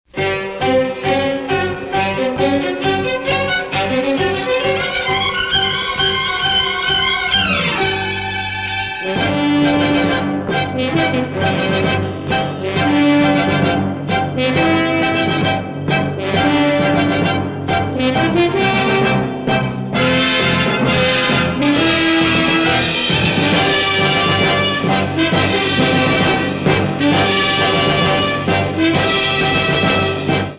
monaural sound from master tapes